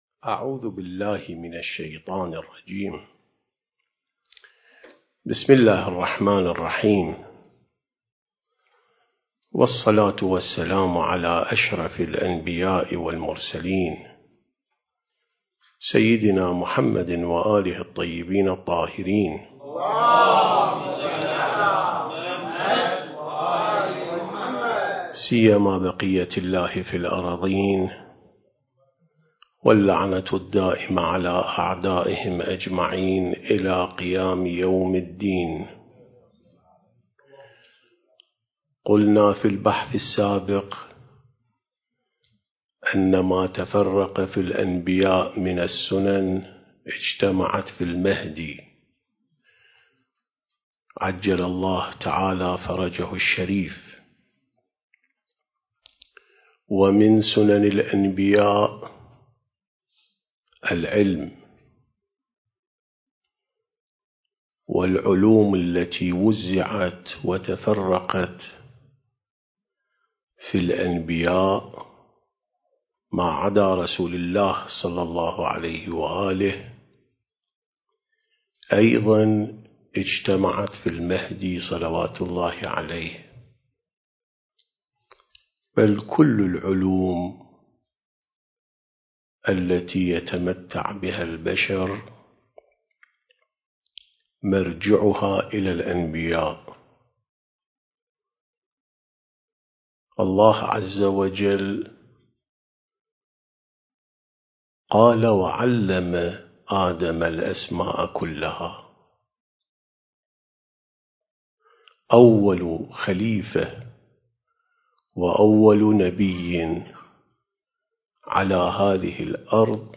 سلسلة محاضرات عين السماء ونهج الأنبياء